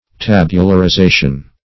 Search Result for " tabularization" : The Collaborative International Dictionary of English v.0.48: Tabularization \Tab`u*lar*i*za"tion\, n. The act of tabularizing, or the state of being tabularized; formation into tables; tabulation.